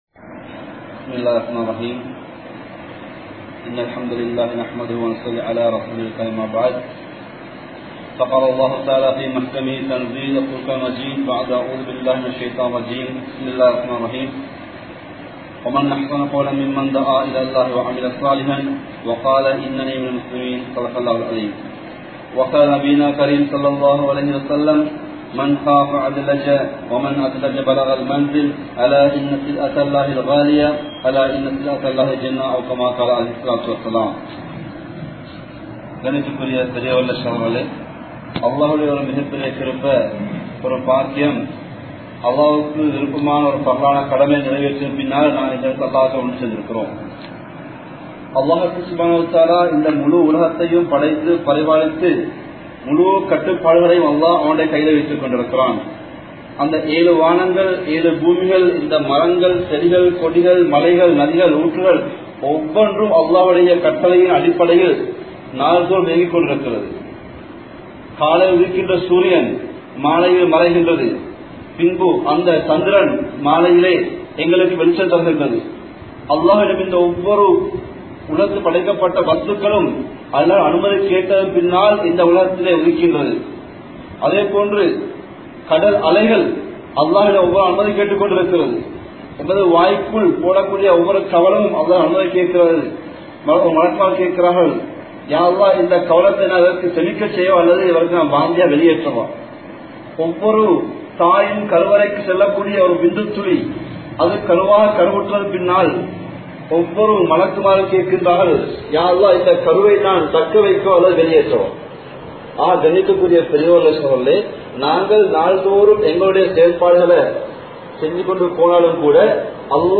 Media & Dhauwath | Audio Bayans | All Ceylon Muslim Youth Community | Addalaichenai
Masjidhul Hudhah